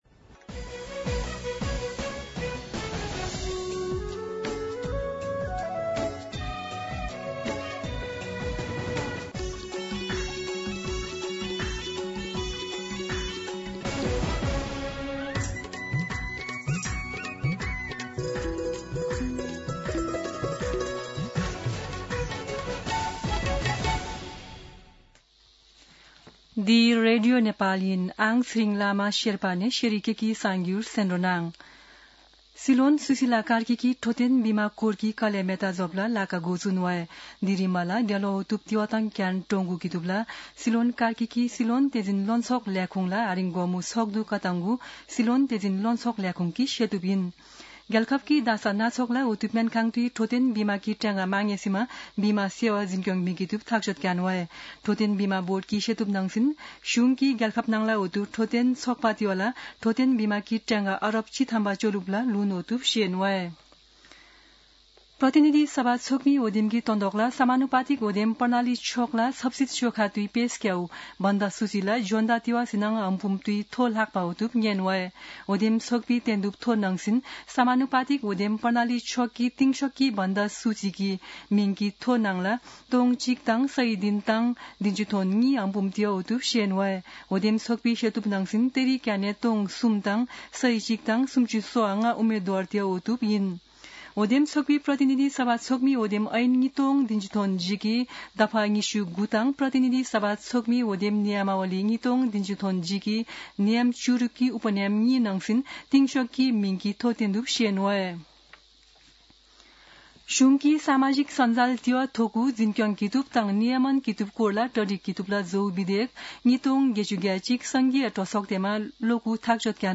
शेर्पा भाषाको समाचार : २१ माघ , २०८२
Sherpa-News.mp3